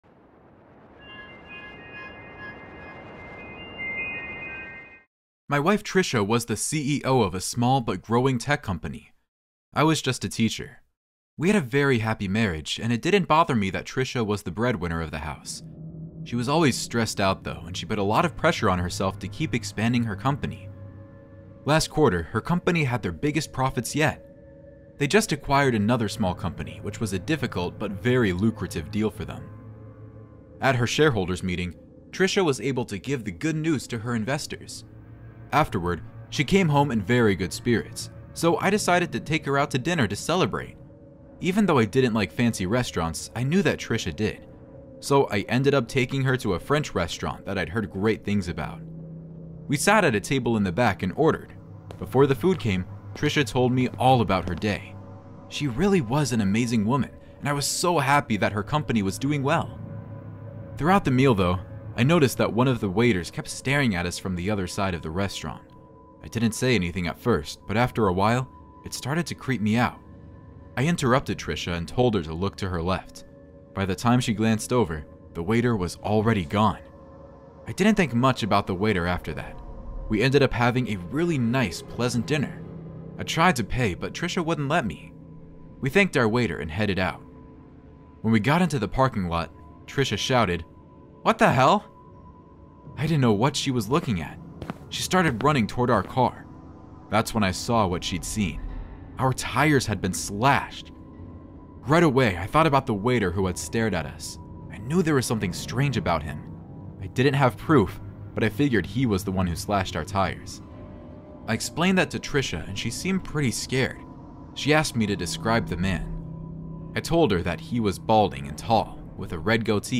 Before the story begins, Scary Stories wants you to know something important: all advertisements are placed at the very beginning of each episode so nothing interrupts the experience once the darkness settles in.